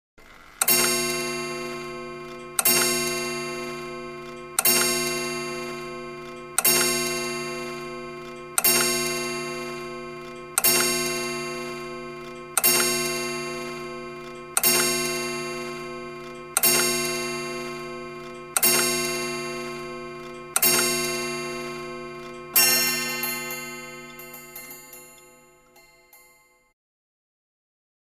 Clock 8, 19th Century, Strikes 12; 19th Century Mantelpiece Clock Stikes 12 O'clock With Light Brass 'coil' Bell And Light Clicking And Whirring Mechanism